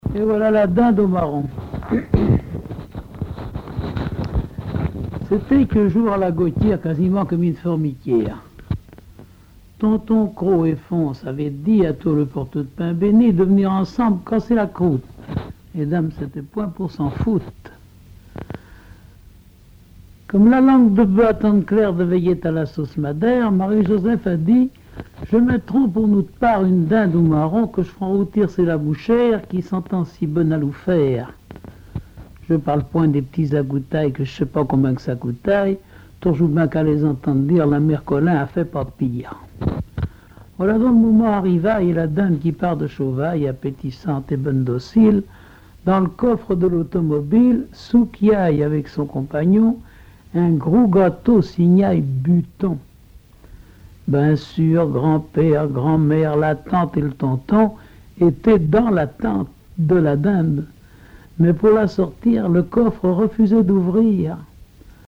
Genre sketch
Récits et chansons en patois
Catégorie Récit